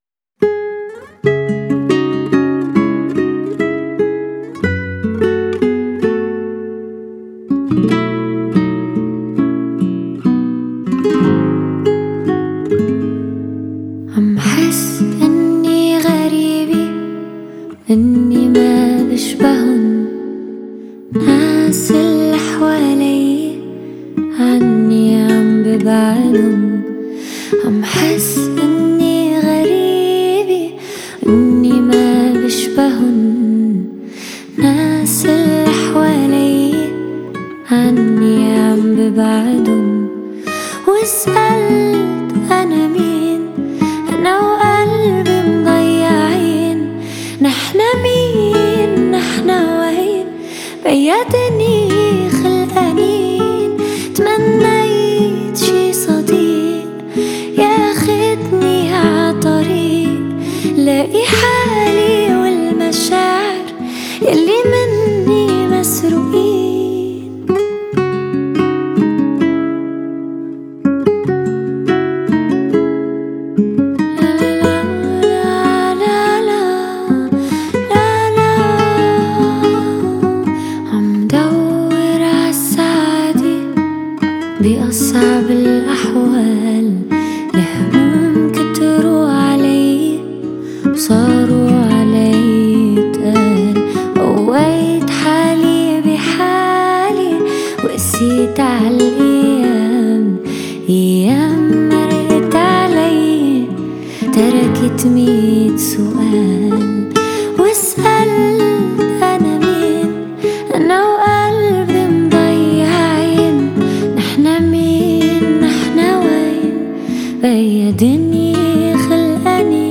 نسخة صوتية أكوستيك